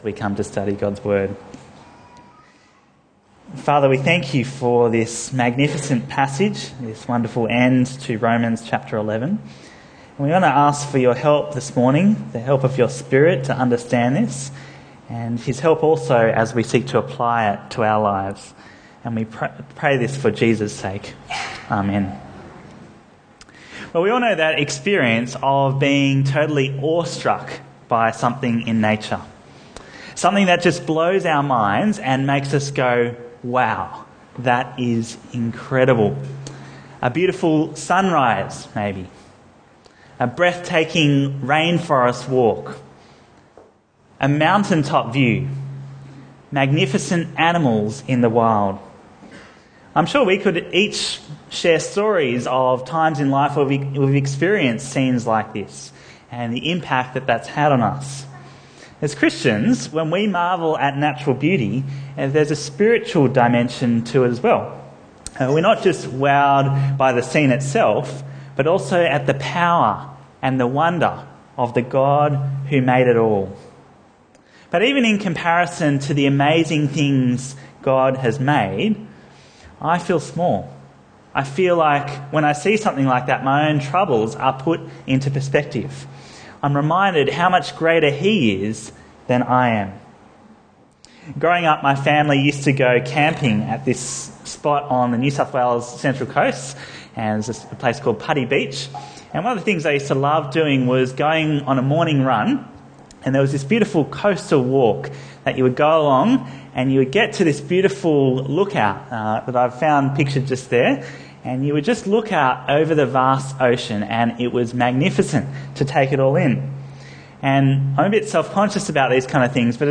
Bible Talks Bible Reading: Romans 11:33-36